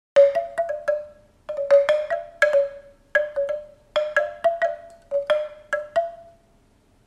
Bambusová zvonkohra – 50 cm
Bambusová zvonkohra na rozdiel od ostatných zvonkohier vydáva jemný, očarujúci a nevtieravý zvuk.
Táto zvonkohra je vyrobená z bambusu a kokosu. Ručné tónovanie a výber rôznych veľkostí rúrok, z ktorých každá vydáva iný zvuk, vytvárajú jedinečnú melódiu už pri najmenšom vánku.
Zvonkohra-bambusova-50-cm.mp3